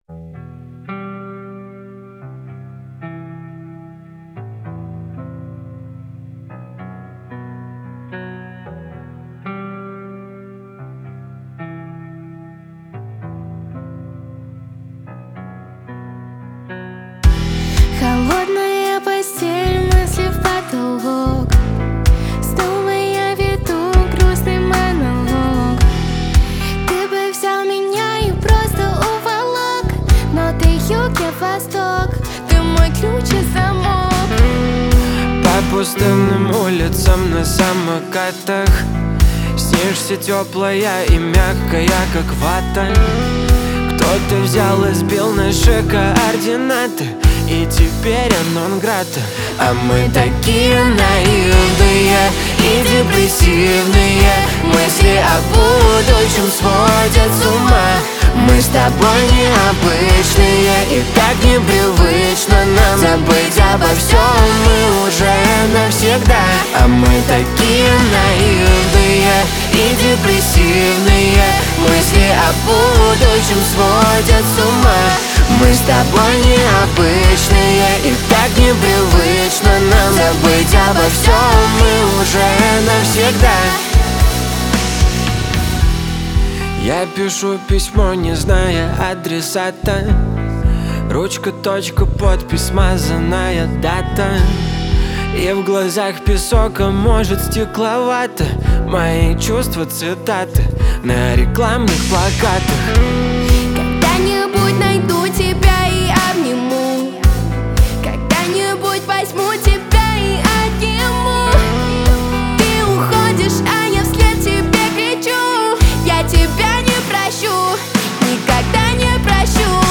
Жанр:Русские новинки / OSTСаундтреки